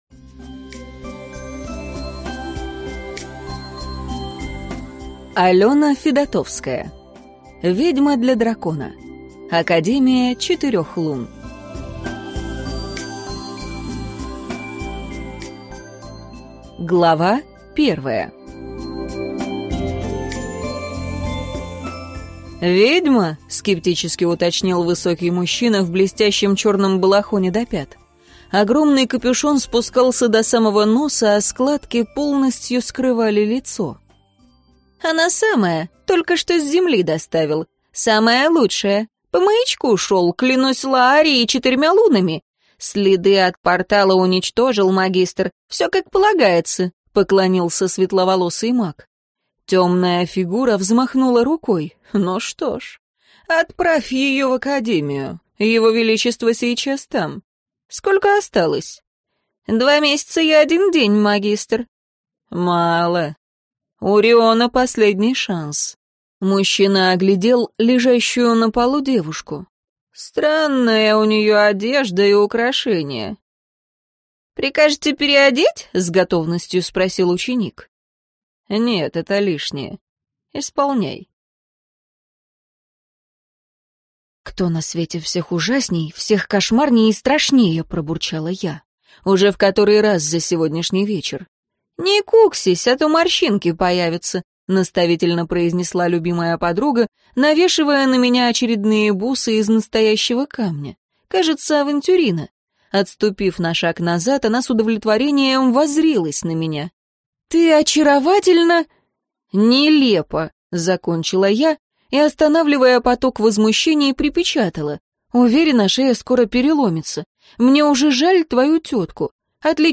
Аудиокнига Ведьма для дракона. Академия Четырех Лун | Библиотека аудиокниг